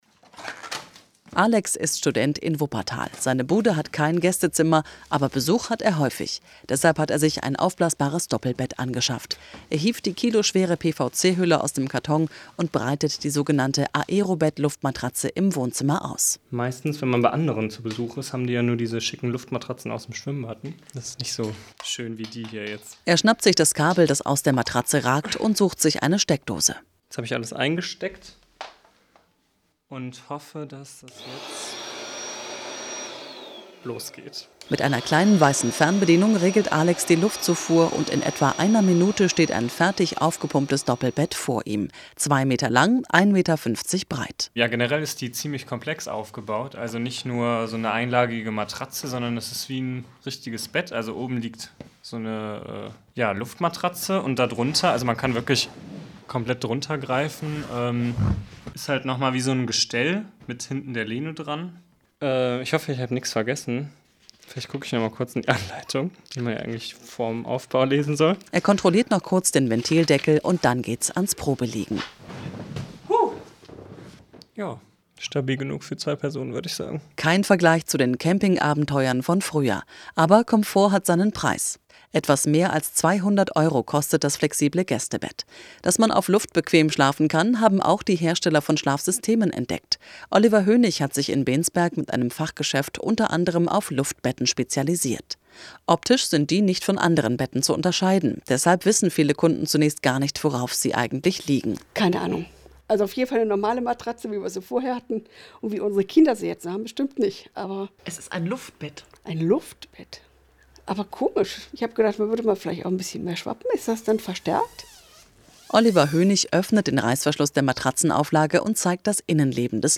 WDR5_Lebensart_Luftbetten.mp3